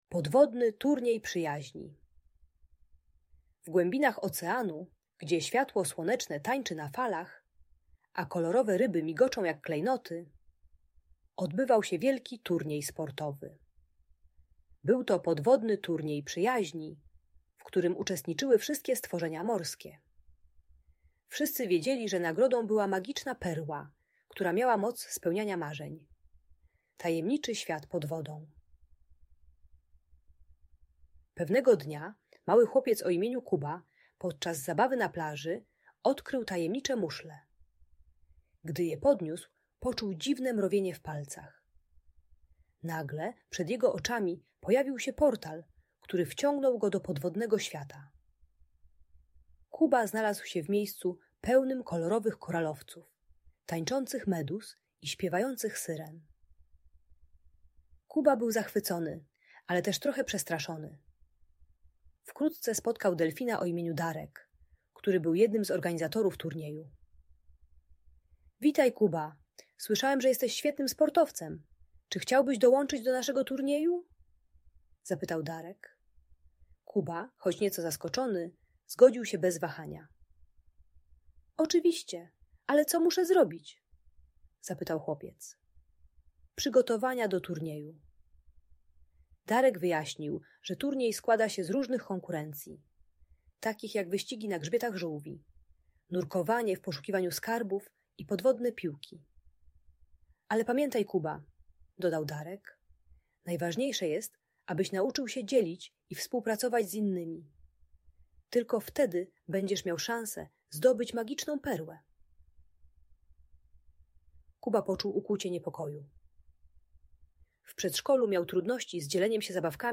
Podwodny Turniej Przyjaźni - Magiczna Opowieść - Audiobajka